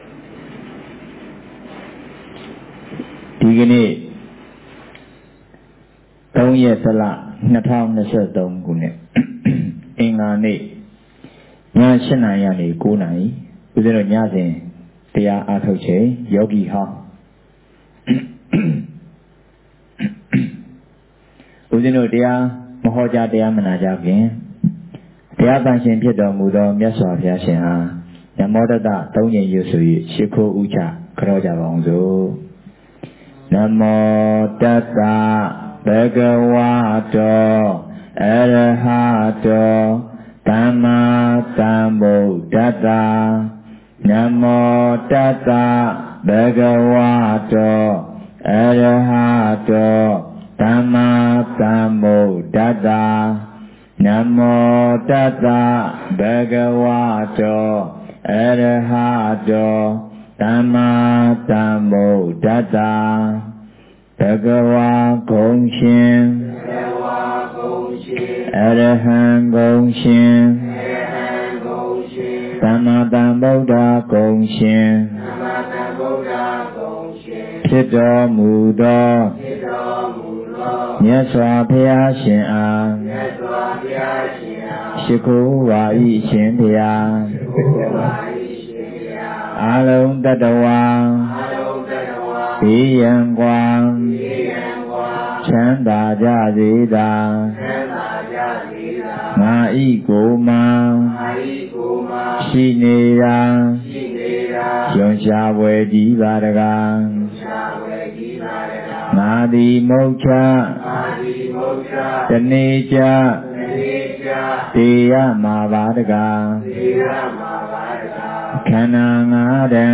Jan03 2023 ညစဉ်တရားပွဲ